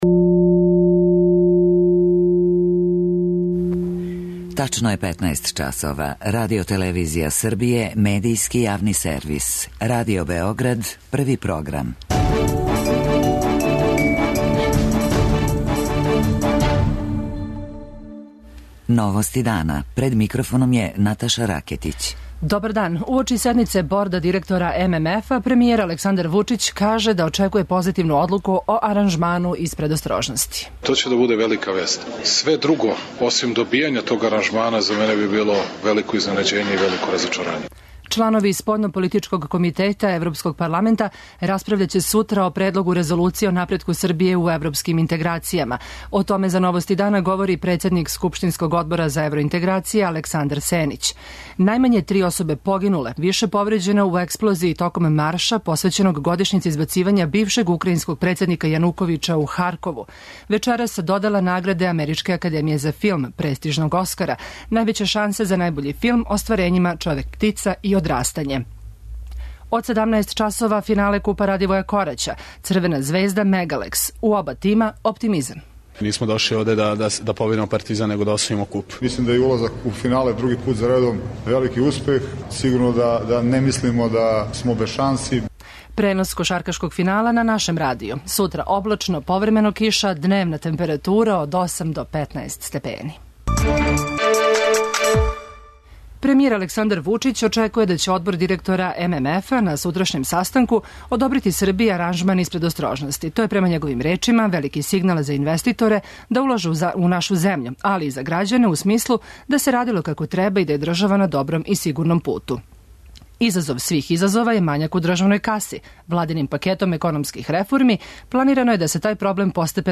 О томе за Новости дана говори председник скупштинског одбора за евроинтеграције Александар Сенић.